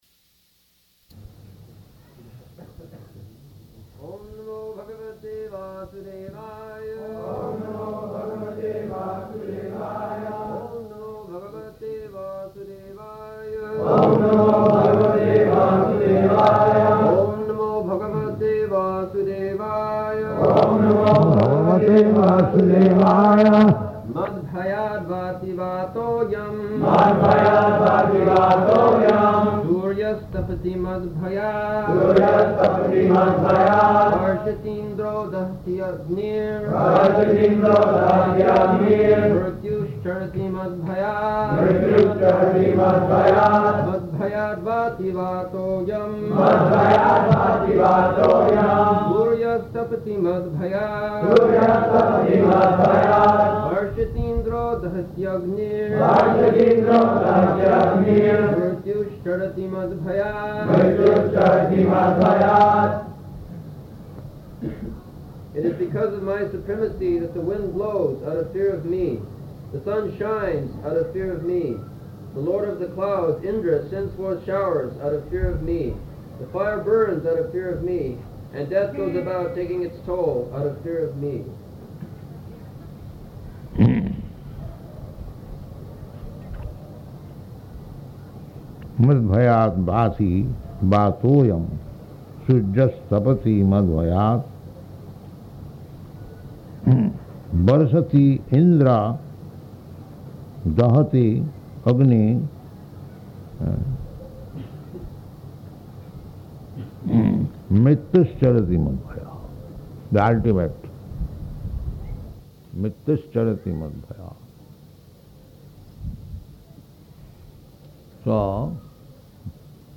December 10th 1974 Location: Bombay Audio file
[poor recording]
[Prabhupāda and devotees repeat] [leads chanting of verse, etc.]